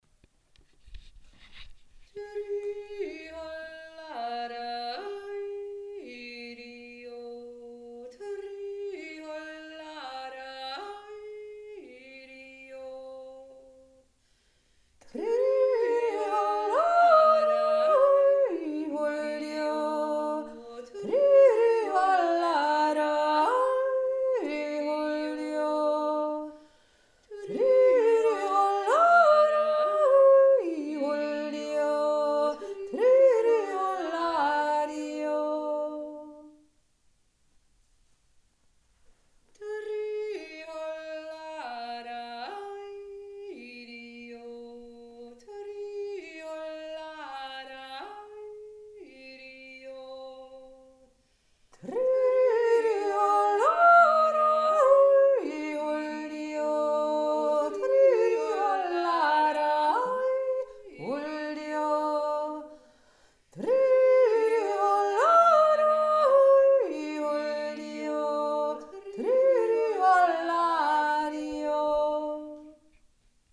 Zweistimmig
Hohe Stimme